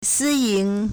私营 (私營) sīyíng
si1ying2.mp3